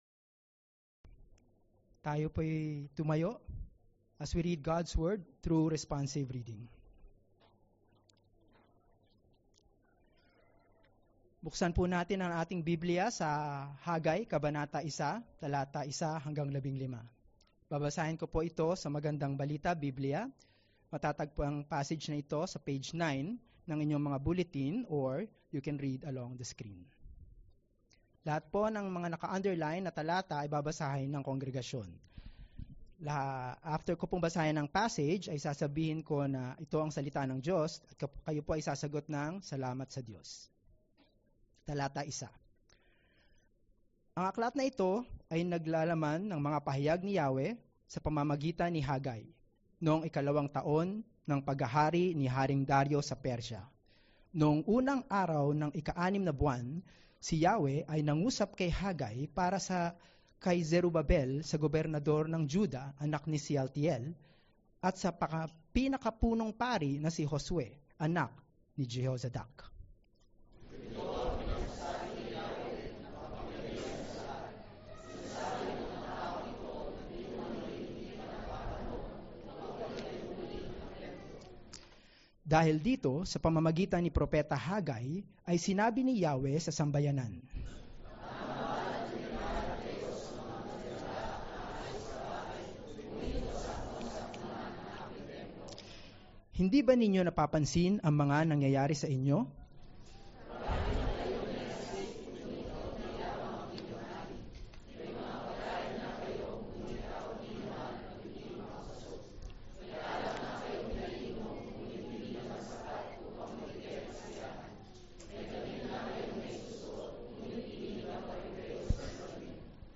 April-7-Sermon.mp3